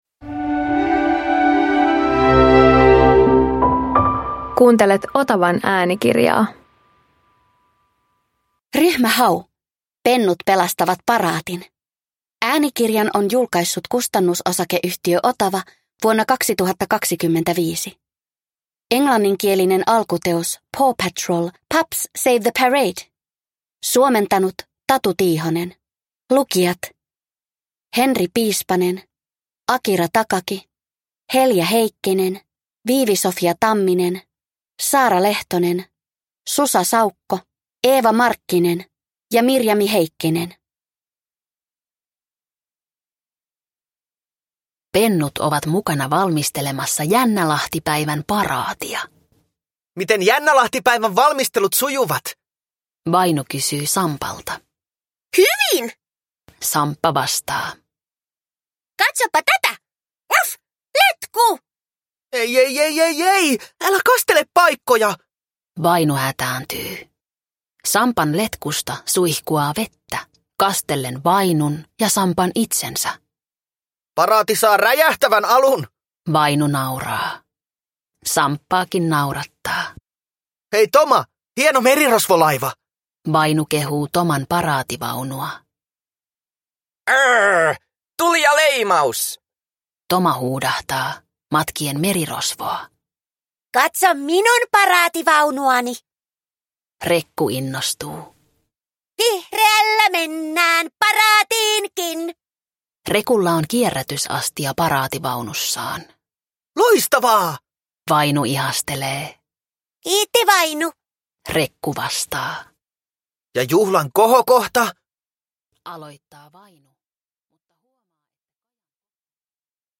Ryhmä Hau - Pennut pelastavat paraatin – Ljudbok